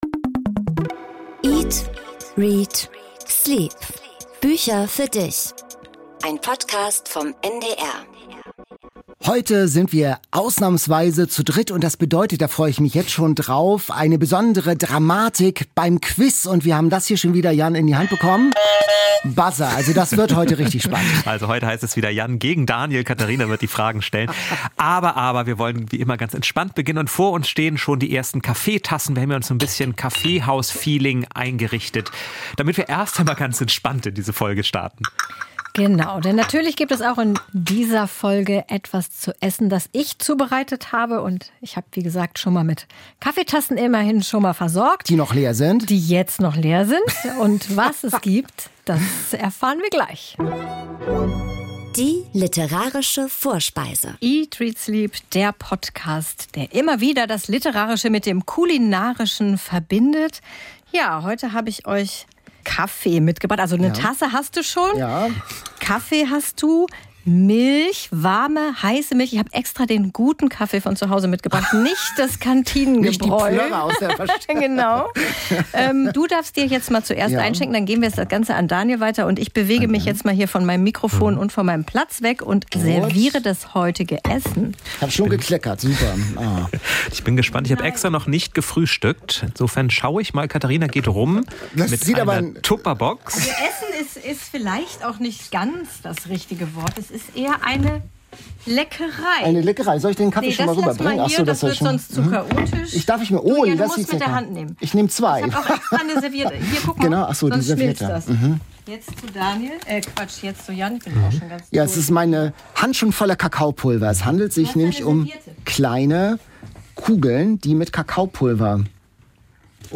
Beschreibung vor 4 Jahren Kaffeehaus-Stimmung bei den drei Hosts, aber Uneinigkeit in der Bestseller-Challenge und in der Wolldeckenbuch-Frage Was ist eigentlich Literatur? Muss sie anstrengen, darf sie entspannen?